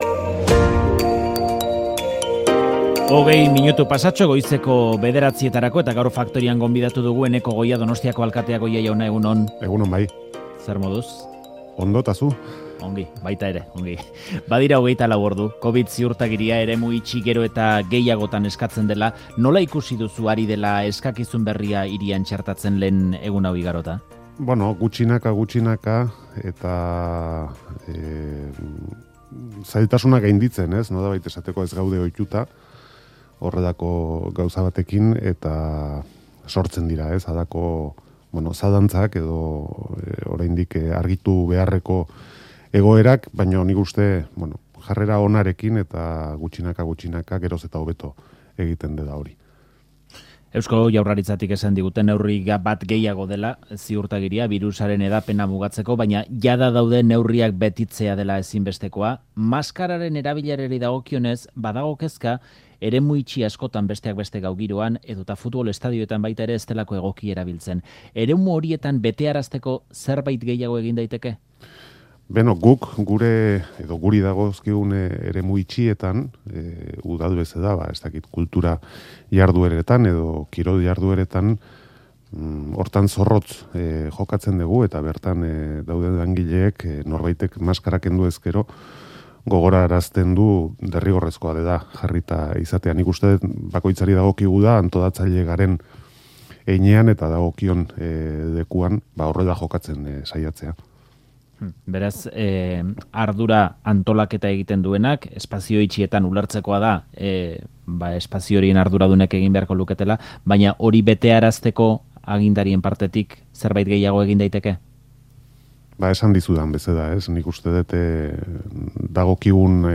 Audioa: Donostiako alkateak azaldu du udala asteartean dela ematekoa Olentzeroren eta Errege Magoen desfileei buruzko xehetasunak. Arduraz jokatzeko deia egin die herritarrei, eta COVID ziurtagiriaren hedapenaz, neurriak betearazteko eskatu die jardueretako antolatzaileei.